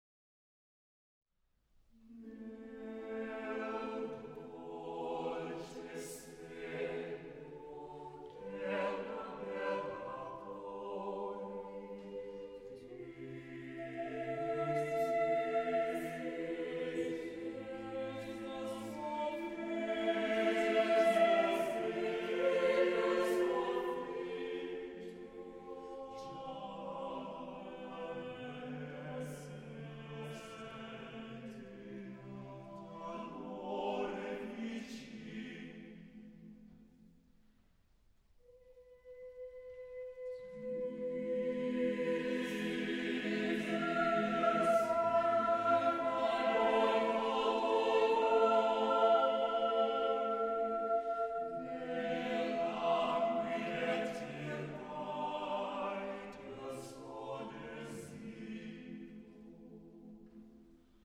Epoque: 16th century  (1550-1599)
Genre-Style-Form: Madrigal
Mood of the piece: tragic ; painful
Type of Choir: SAATTB  (6 mixed voices )
Tonality: A minor
Discographic ref. : Internationaler Kammerchor Wettbewerb Marktoberdorf